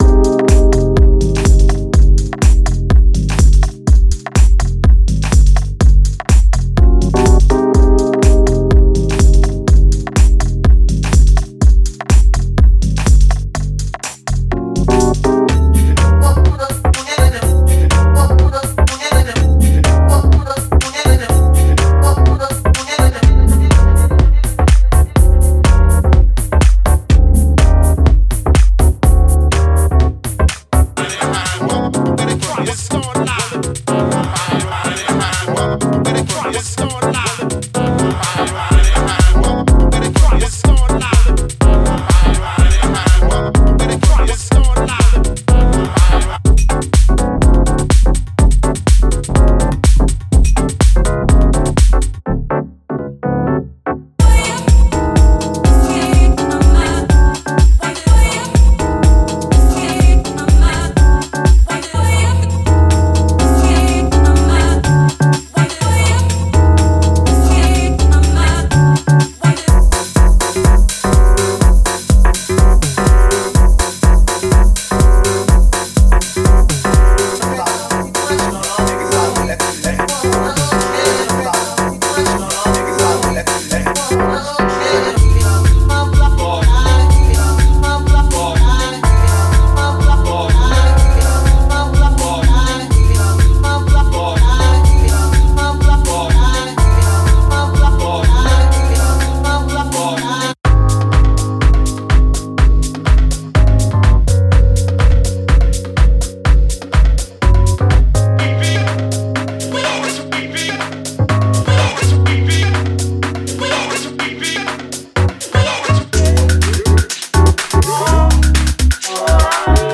50 Bass Loops
50 Chord Loops
50 Beat Loops With Kick
50 Vocal Loops